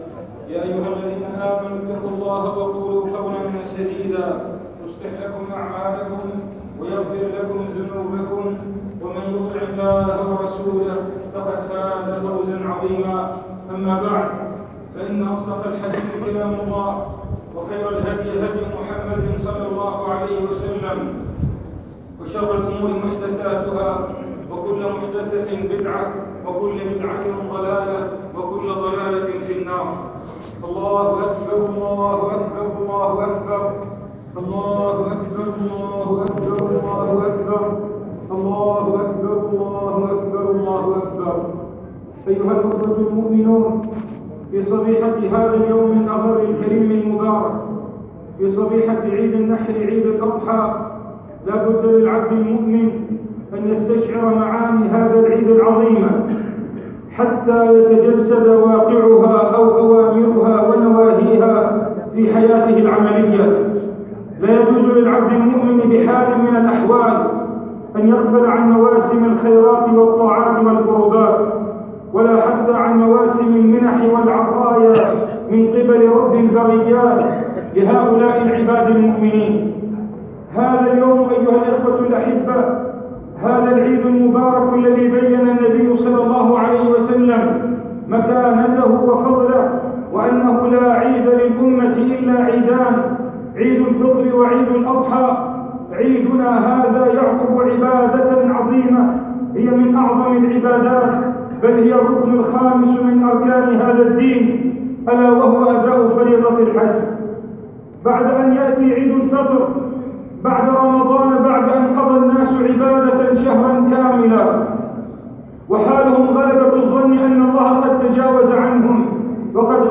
خطبة عيد الأضحى المبارك